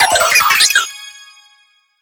Cri de Terapagos dans Pokémon HOME.